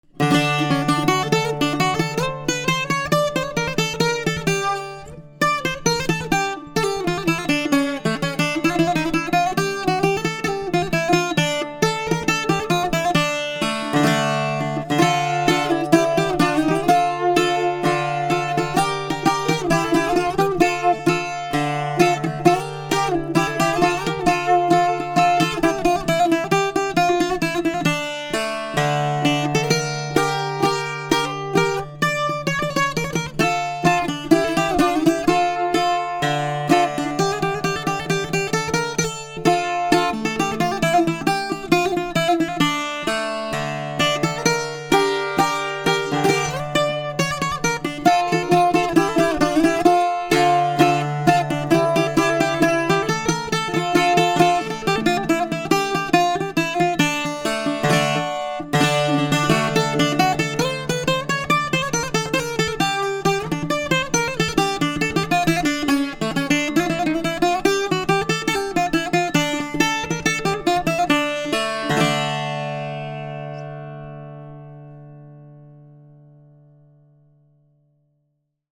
Παραδοσιακό τρίχορδο από σφενδάμι με πολύ παχύ και γλυκό ήχο ρεμπέτικο προπολεμικό, με τα παρακάτω χαρακτηριστικά:
μουσικό όργανο μπουζούκι τρίχορδο.